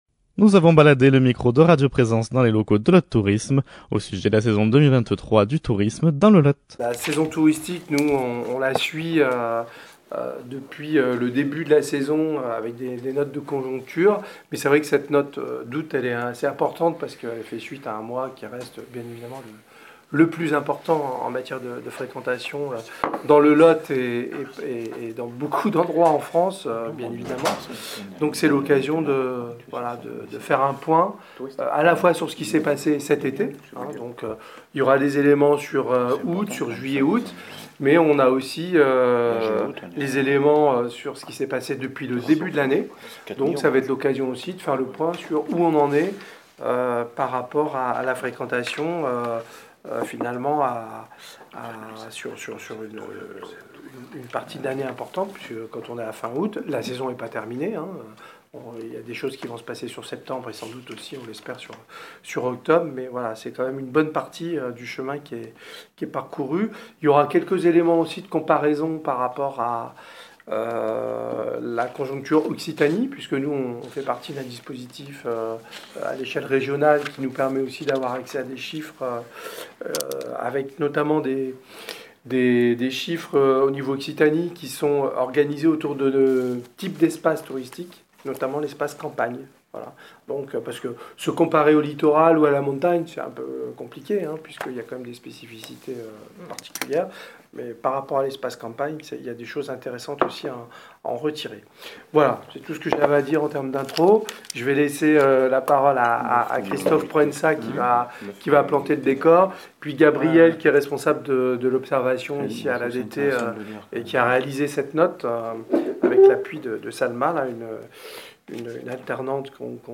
Conférence de Presse 2023 LOT TOURISME